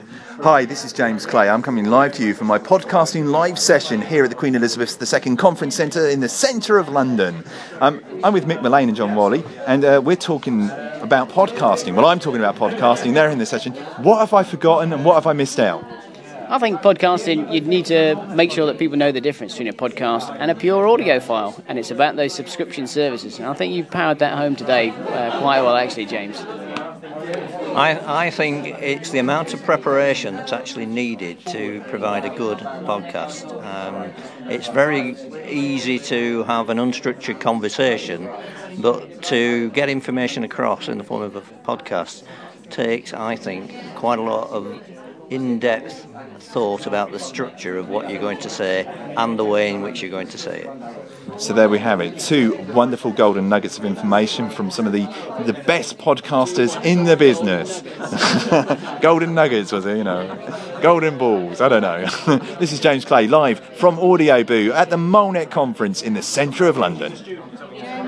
Live at MoLeNET 2010